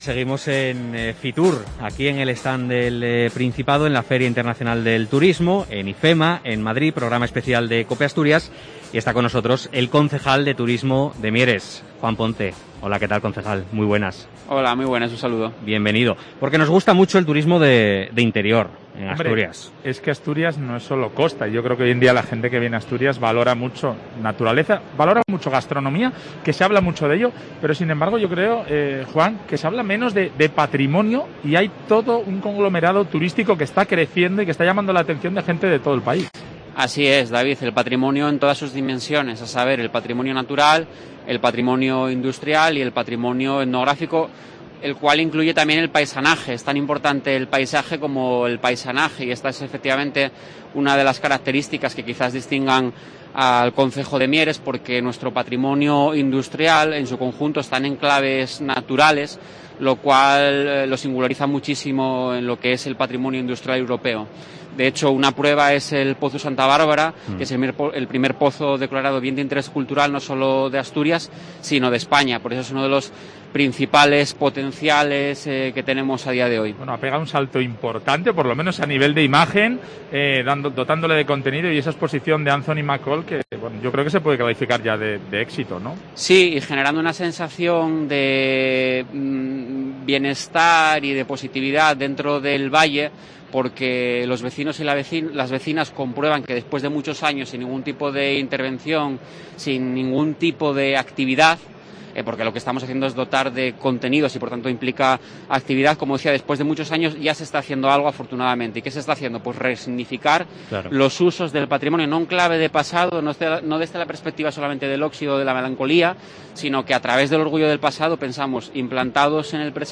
El concejal de Turismo mierense, Juan Ponte, ha estado en el programa especial de COPE Asturias desde Fitur
Fitur 2022: Entrevista a Juan Ponte, concejal de Turismo de Mieres